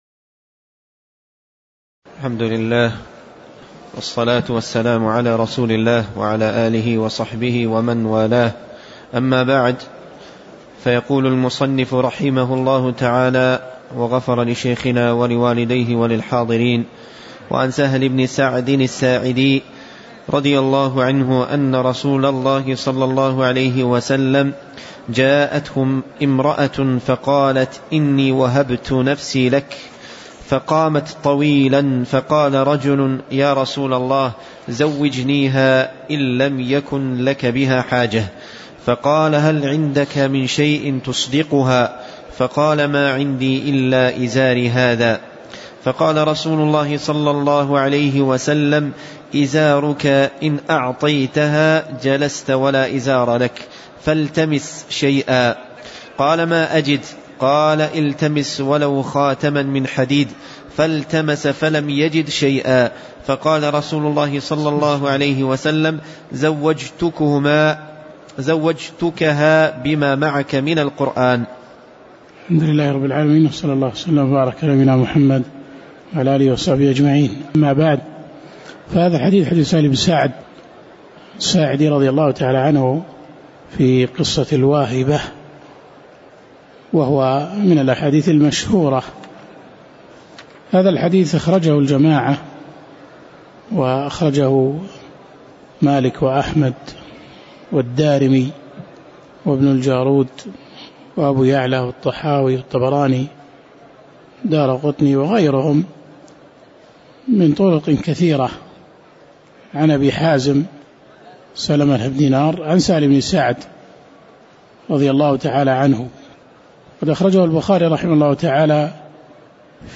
تاريخ النشر ١٢ صفر ١٤٤٠ هـ المكان: المسجد النبوي الشيخ